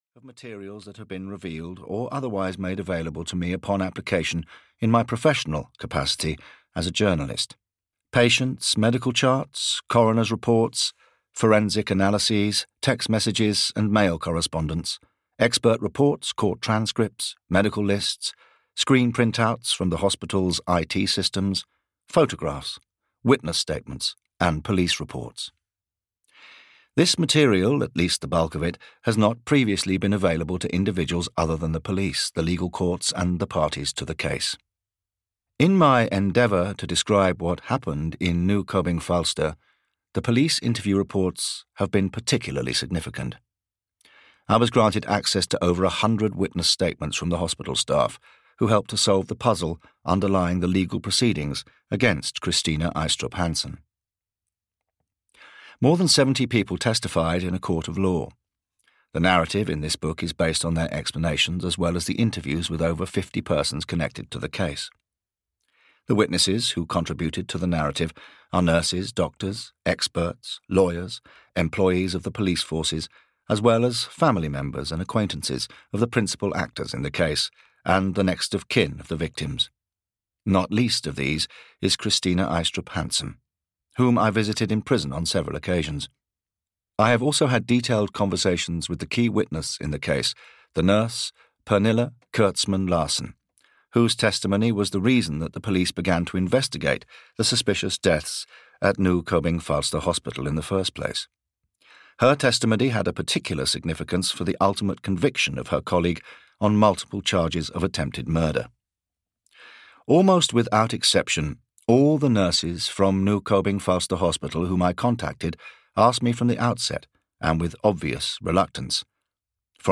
Ukázka z knihy
the-nurse-inside-denmark-s-most-sensational-criminal-trial-en-audiokniha